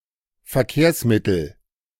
Verkehrsmittel (German: [fɛɐ̯ˈkeːɐ̯sˌmɪtl̩]